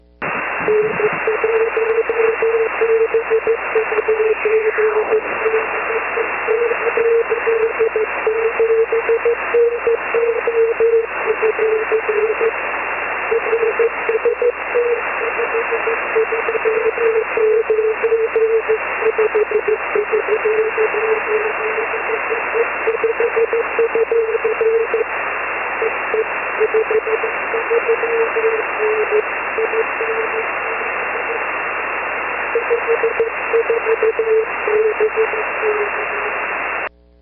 After many years I got the last state on 6 meters! This is a high latitude auroral Es path. Note the rapid fading and warbling of the signal due to rapidly varying doppler shift.